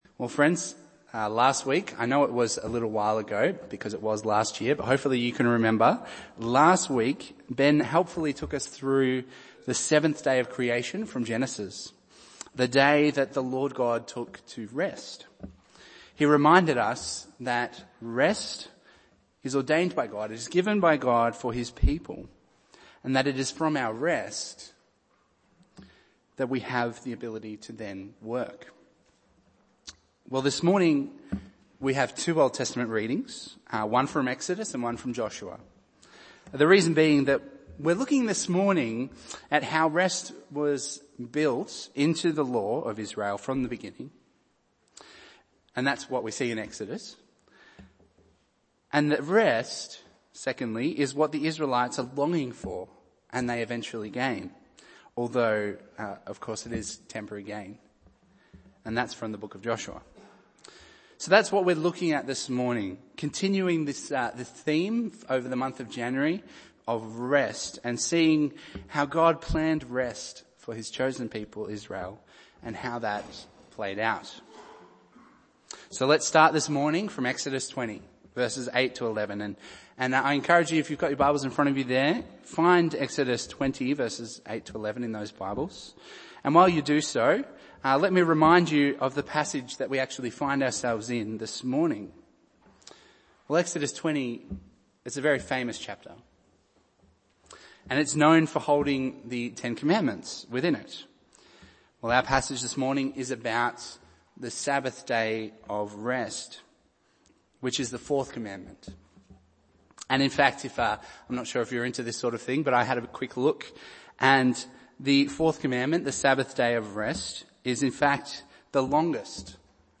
Bible Text: Exodus 20:8-11, Joshua 22:1-6 | Preacher